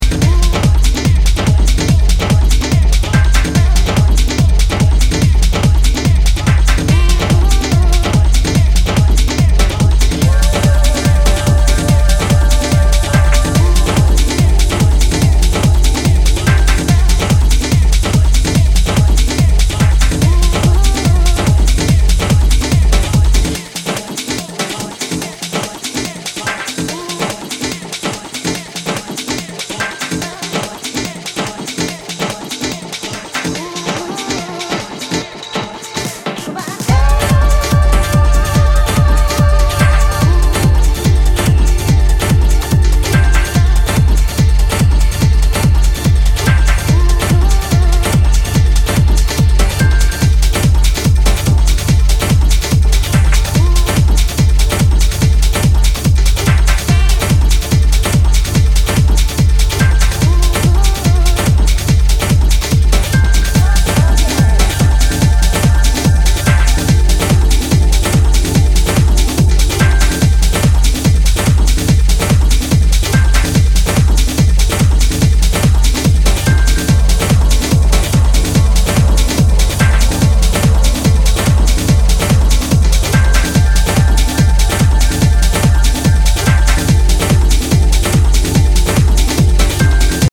six dynamic techno cuts